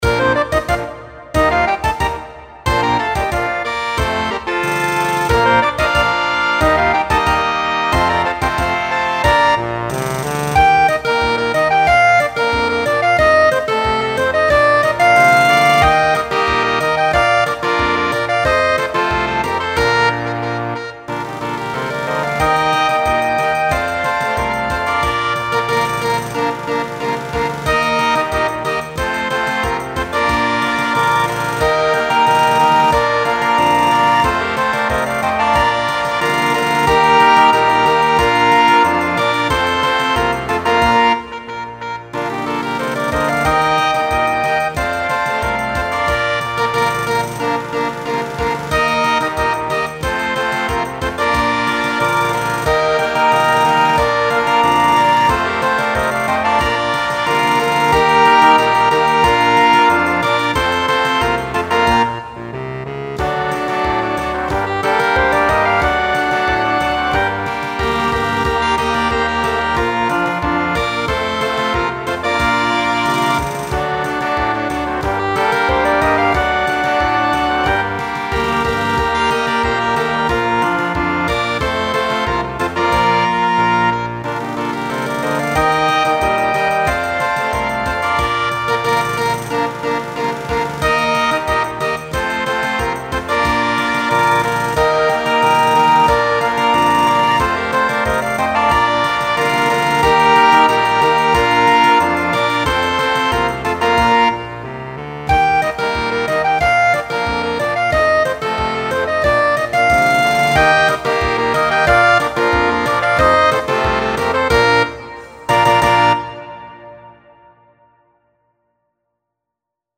Villancico popular instrumentado para banda de música.